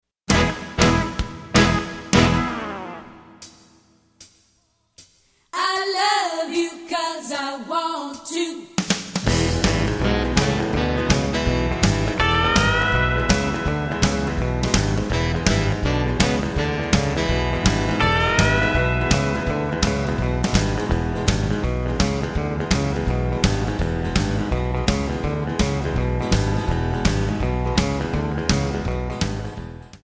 MPEG 1 Layer 3 (Stereo)
Backing track Karaoke
Country, Musical/Film/TV, 1990s